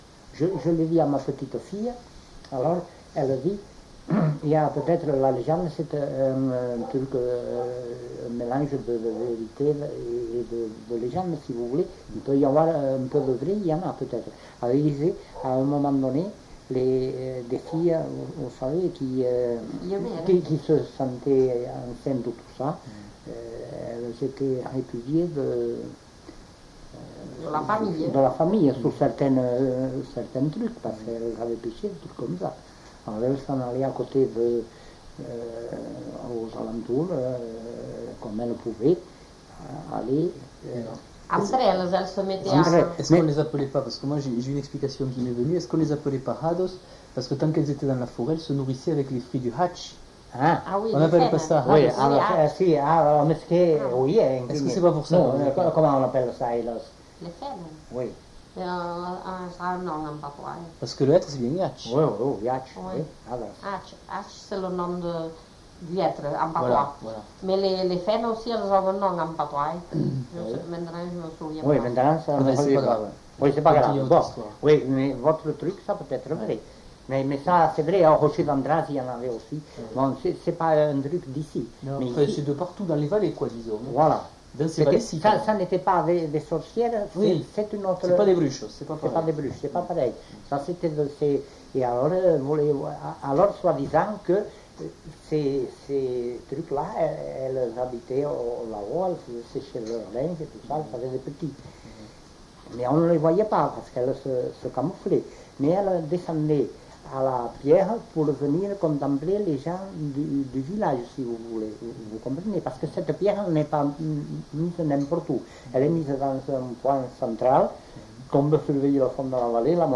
Lieu : Eylie (lieu-dit)
Genre : témoignage thématique